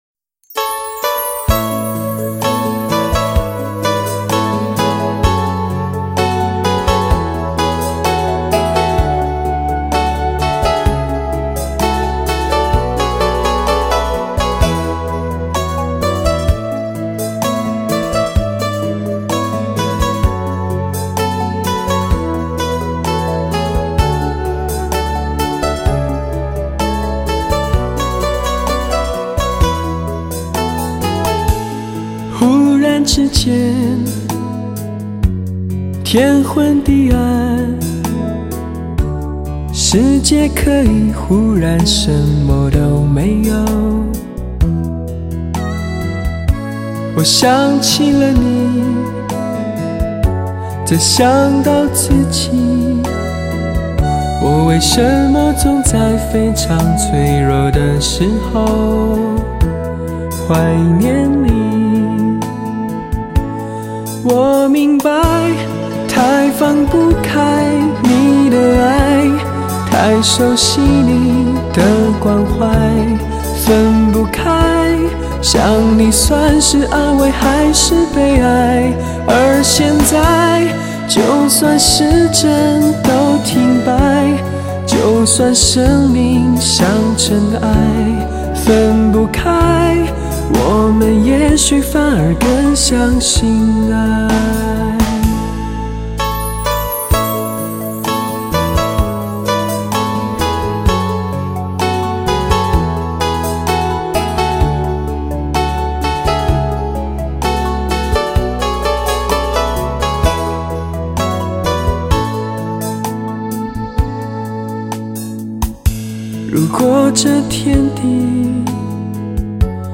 是流行乐坛上不可多得的发烧男声